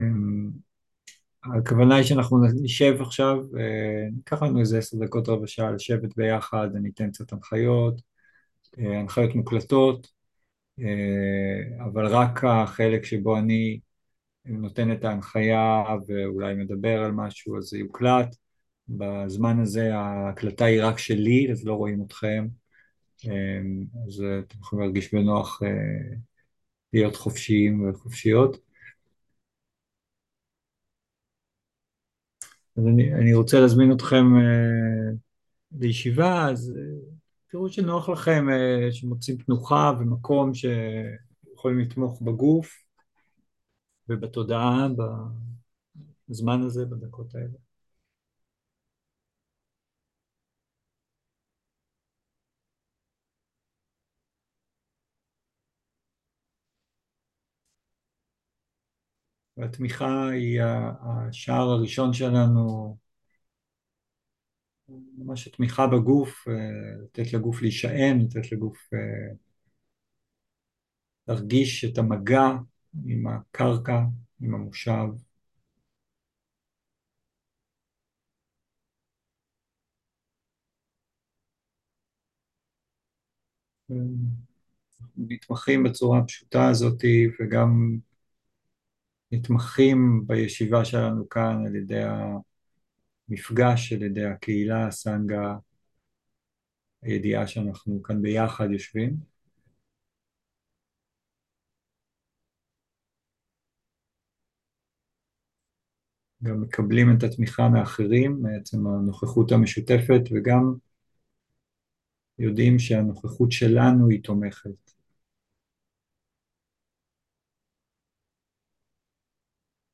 11.10.2023 - מרחב בטוח - נשימה, מרחב, מפגש - תרגול מונחה